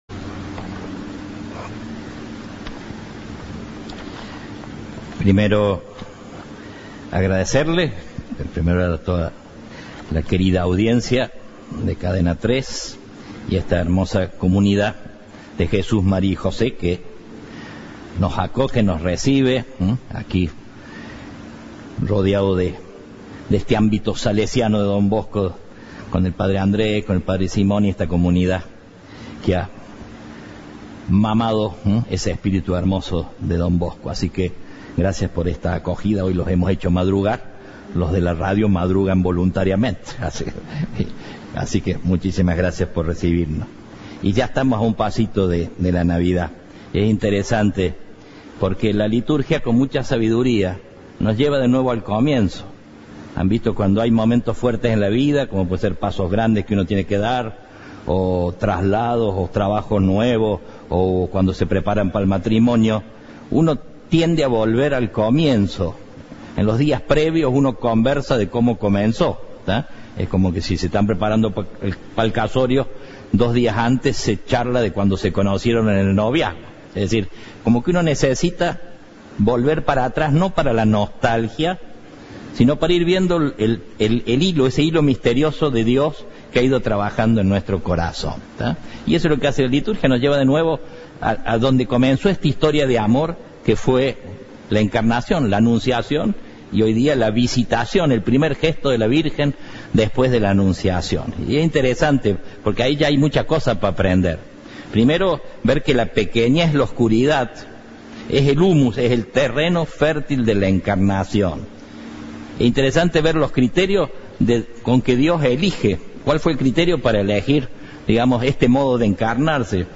Monseñor Rossi inicia su arzobispado de cara a la Navidad - Santa Misa - Cadena 3 Argentina
En su primera misa como arzobispo, el Padre Rossi agradeció a la Cadena 3 la transmisión y pidió para estas fiestas poner la mirada en los sencillos, nuestra familia, nuestros abuelos, nuestros solitos, nuestros enfermos.